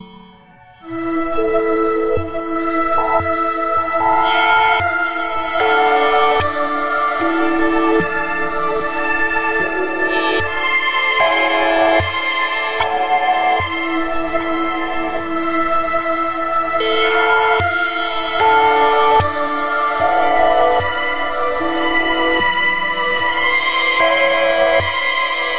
阴森恐怖的悬疑质感
描述：邪恶，可怕，悬疑，阴森恐怖，不和谐，质感
Tag: 邪恶 质感 可怕 悬念